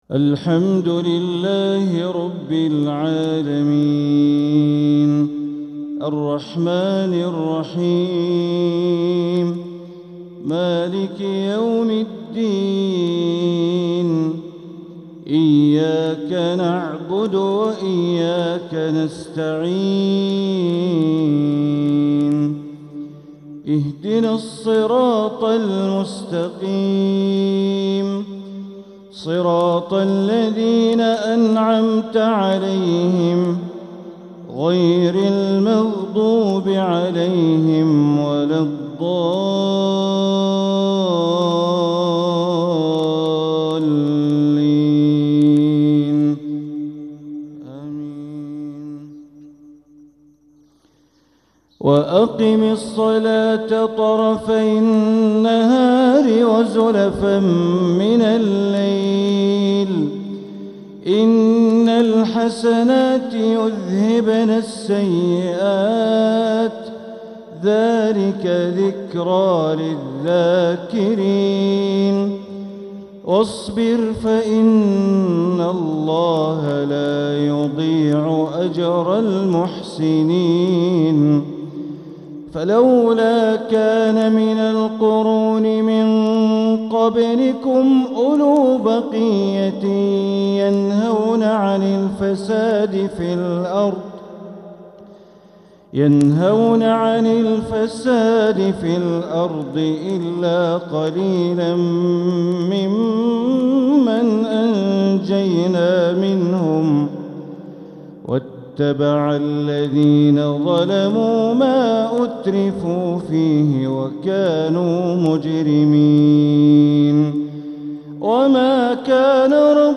تلاوة من سورتي هود والإسراء مغرب السبت ١صفر١٤٤٧ > 1447هـ > الفروض - تلاوات بندر بليلة